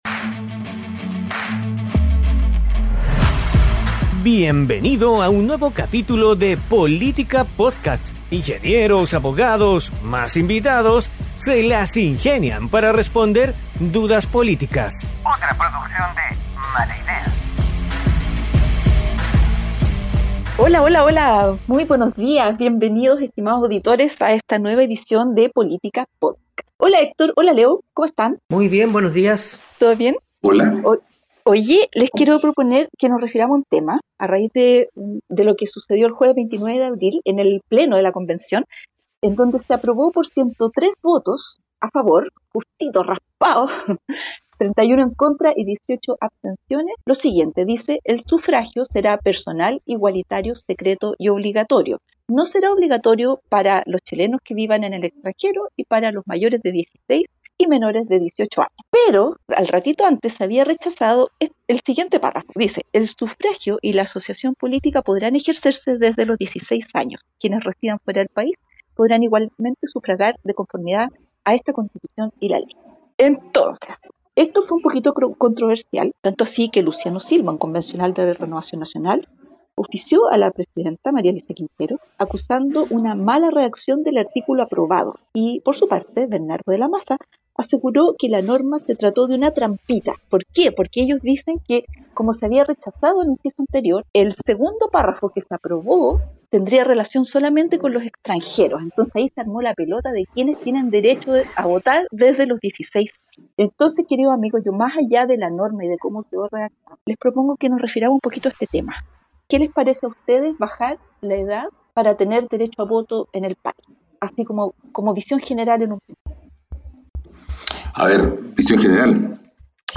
Tres ingenieros se las ingenian para responder dudas políticas